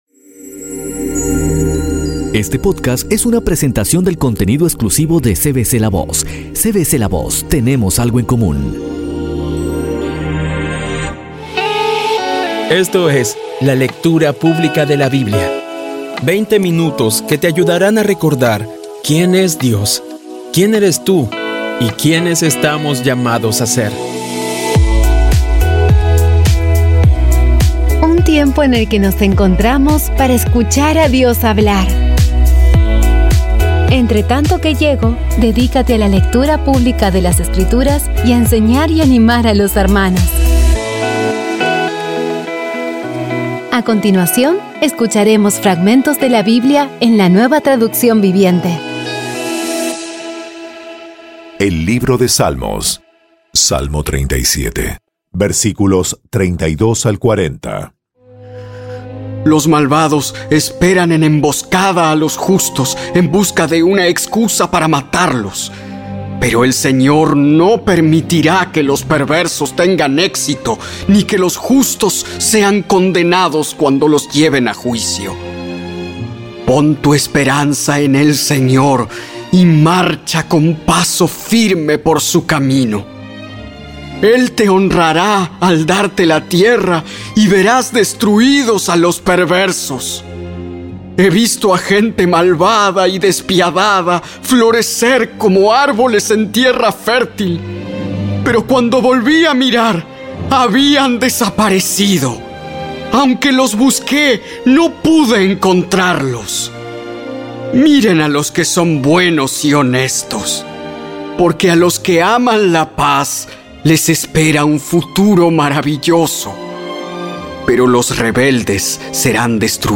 Audio Biblia Dramatizada por CVCLAVOZ / Audio Biblia Dramatizada Episodio 81
Poco a poco y con las maravillosas voces actuadas de los protagonistas vas degustando las palabras de esa guía que Dios nos dio.